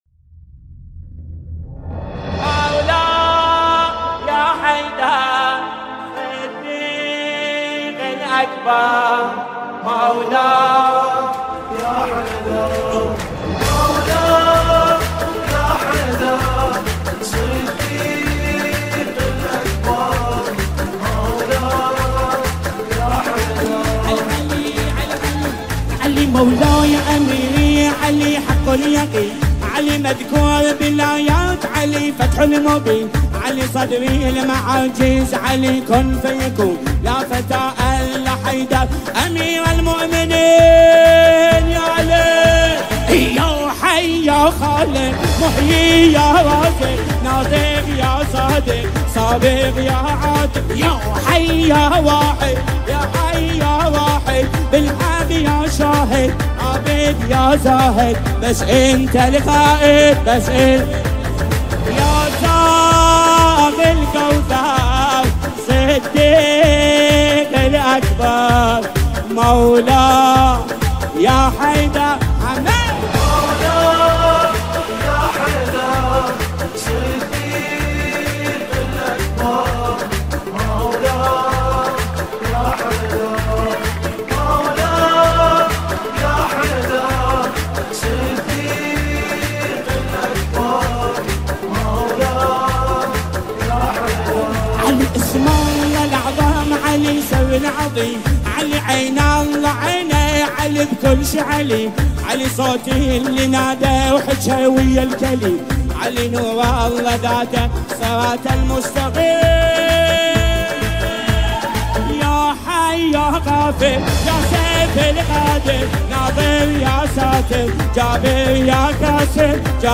با صدای دلنشین
مداحی عربی امام علی علیه السلام